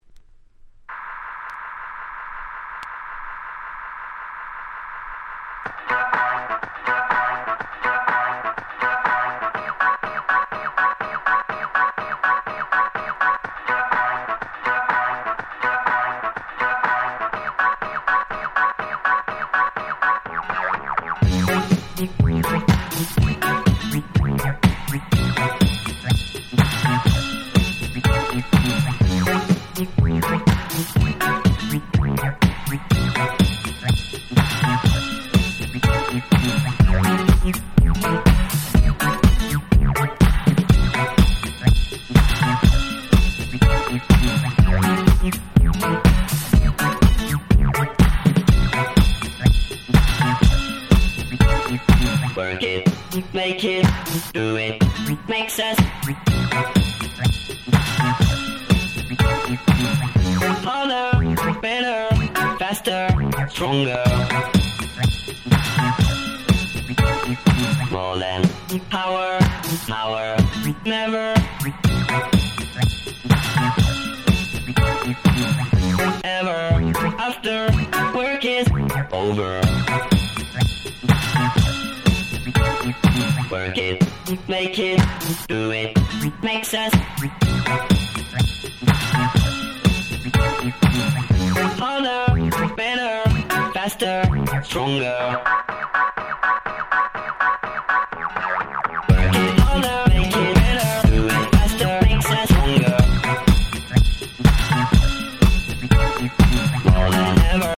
01' 世界的大ヒットDisco !!!